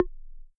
menuclick.ogg